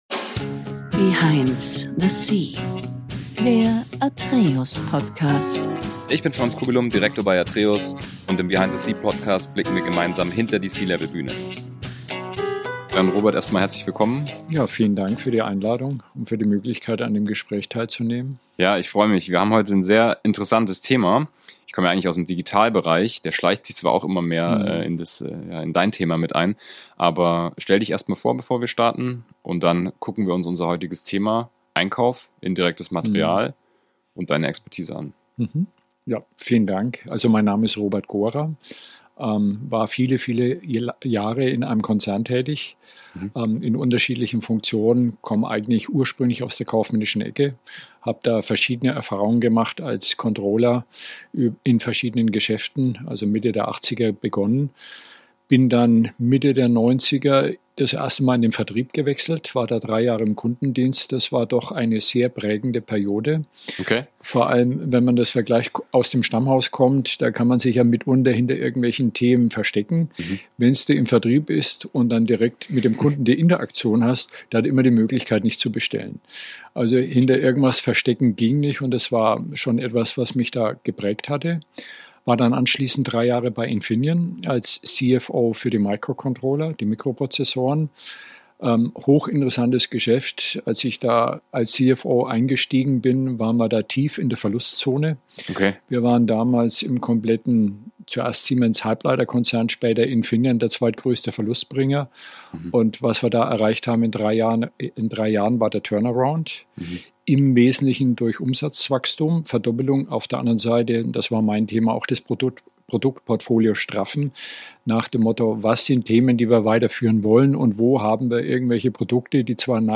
In diesem Gespräch geht es um das Thema indirekten Einkauf.